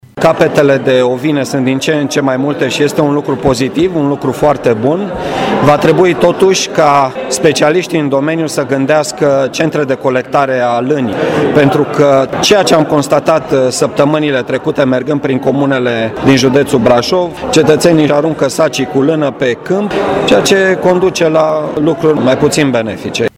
Astfel, dacă numărul de capete de ovine sporește an de an, crescătorii acestor animale nu sunt sprijiniți să colecteze acest produs, ceea ce provoacă mari bătăi de cap oficialităților, a precizat prefectul Marian Rasaliu: